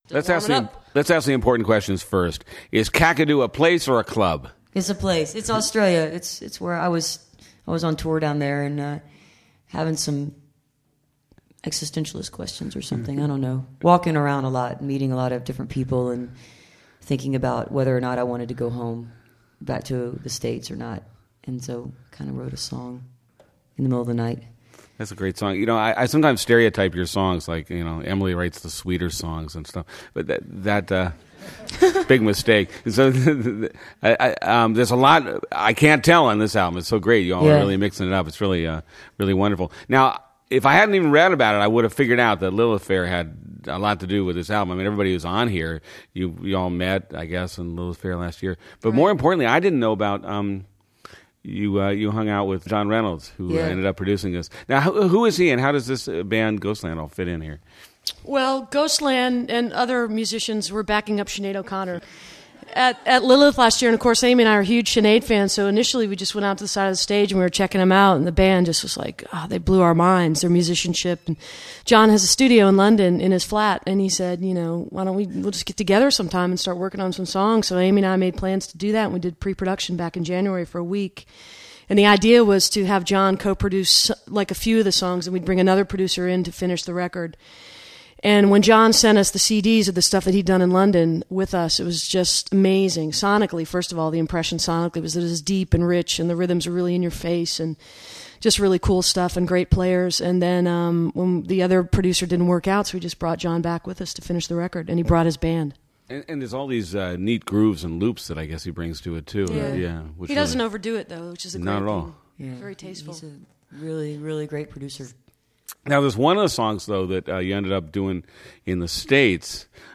lifeblood: bootlegs: 1999-10-05: world cafe recording session at inderay studios - philadelphia, pennsylvania
03. interview (2:55)